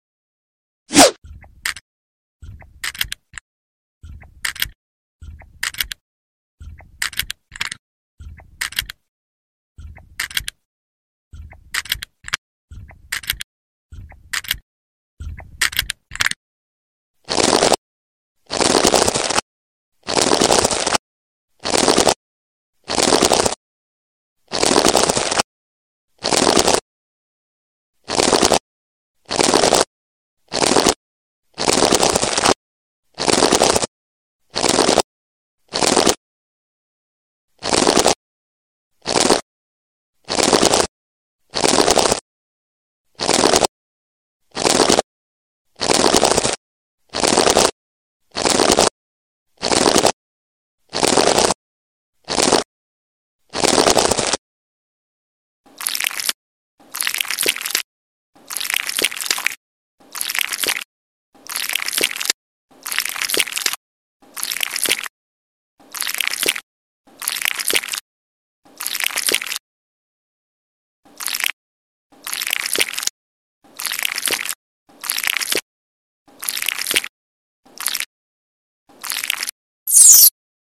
ASMR/ cute ❤ cleaning ASMR sound effects free download